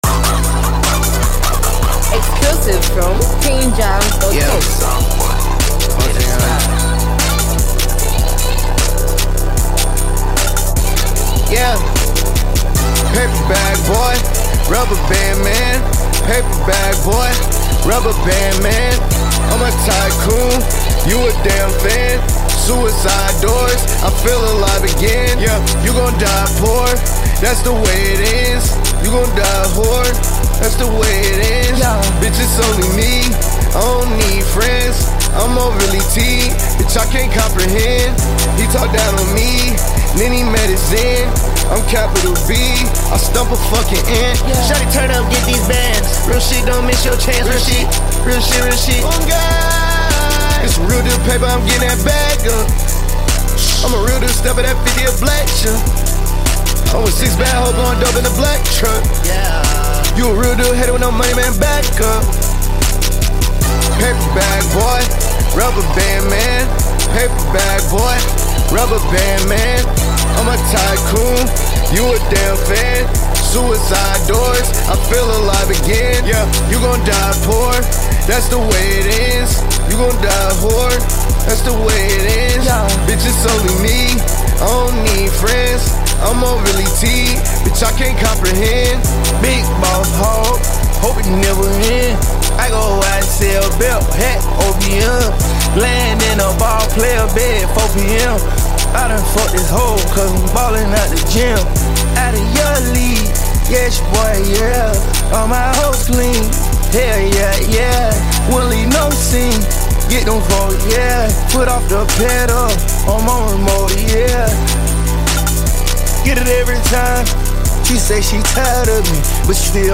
catchy hooks and emotionally tinted delivery